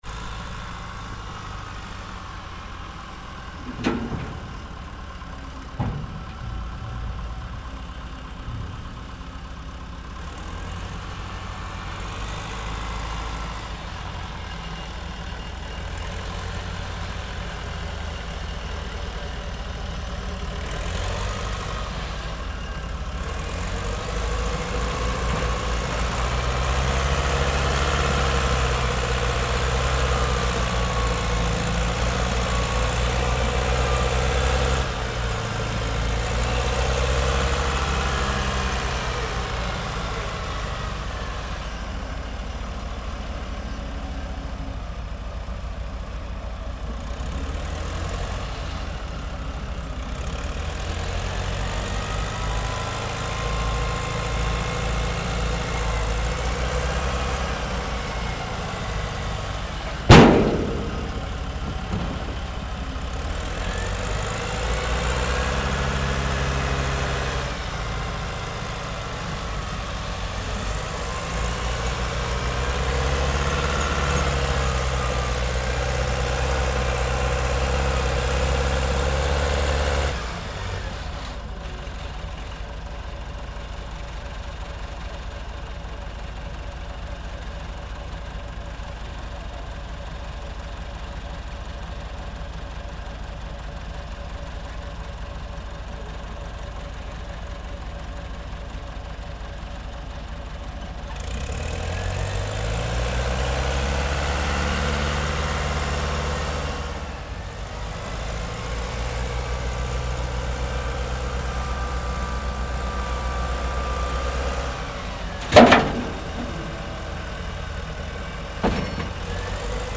Звук бульдозера, заезжающего в грузовик для перевозки